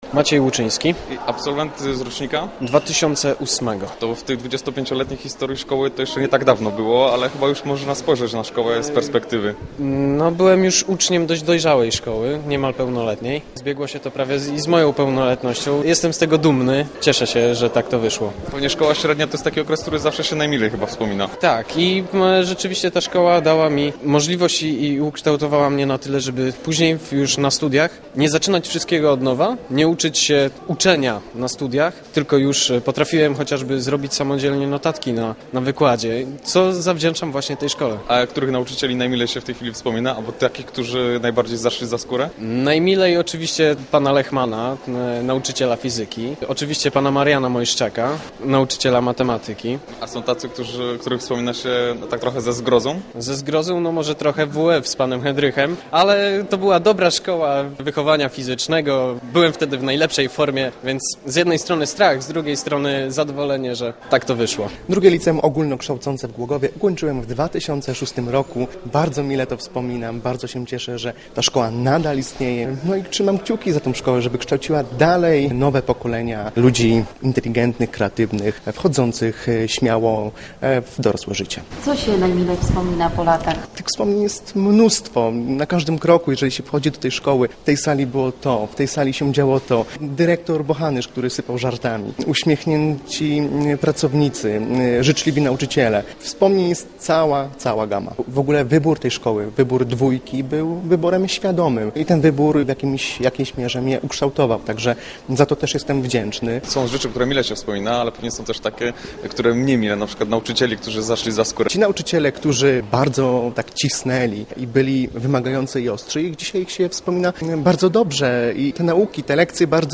W ciągu 25 lat szkoła wyedukowała ponad 3 tysiące uczniów. Ci, którzy przyjechali na jubileusz, mile wspominają lata spędzony w murach Dwójki.